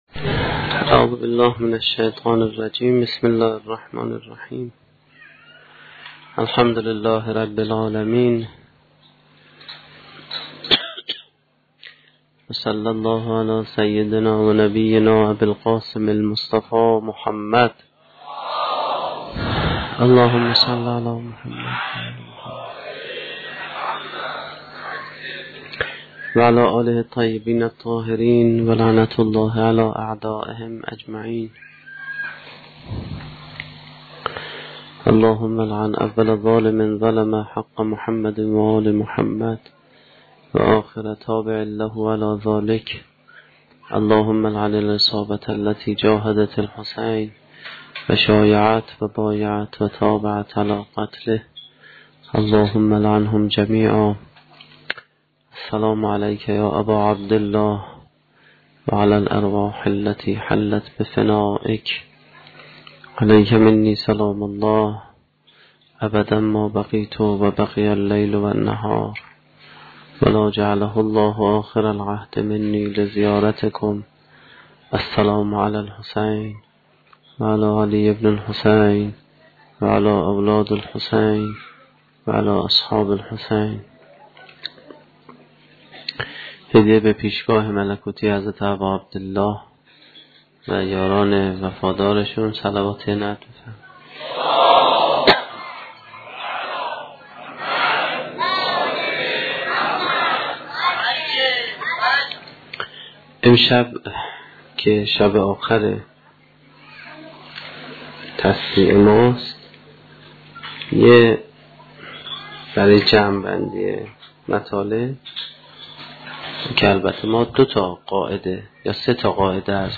سخنرانی دهمین شب دهه محرم 1435-1392